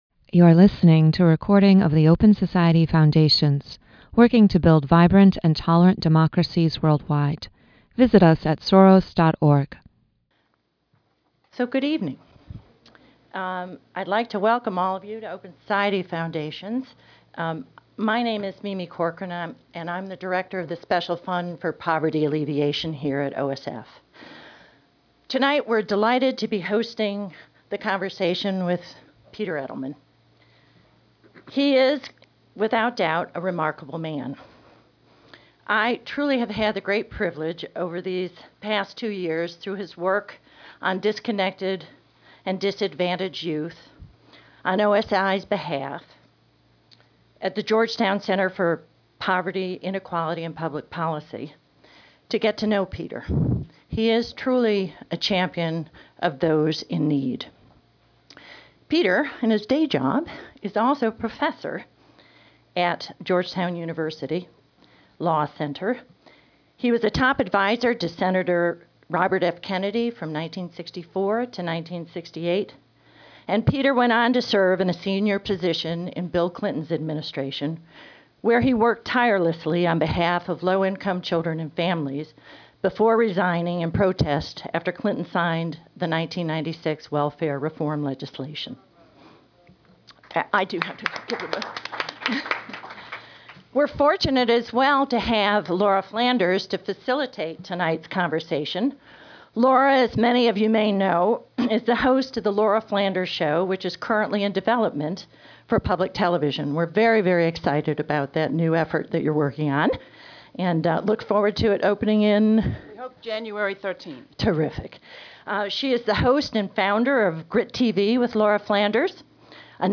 Peter Edelman, who famously resigned from the Clinton administration to protest the treatment of the nation’s poor, joins Laura Flanders, host of GRITtv with Laura Flanders, to discuss the poverty crisis in America today.
makes opening remarks.